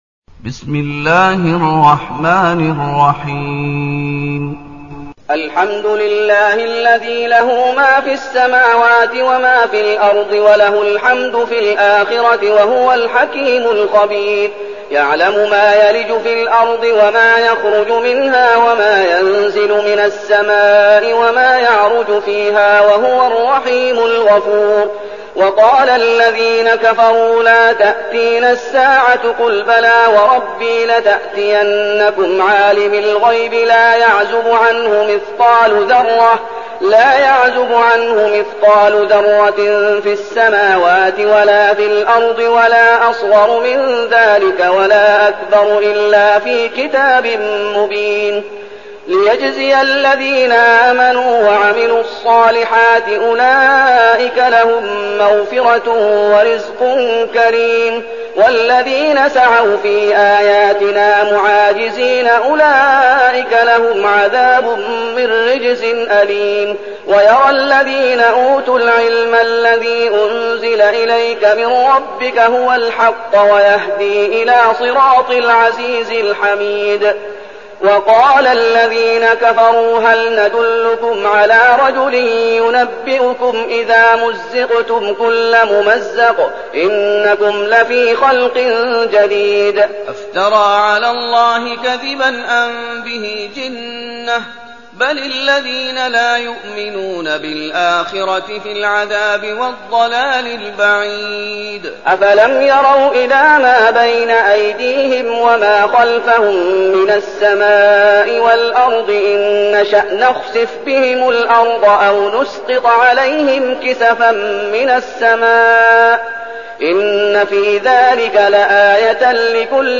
المكان: المسجد النبوي الشيخ: فضيلة الشيخ محمد أيوب فضيلة الشيخ محمد أيوب سبأ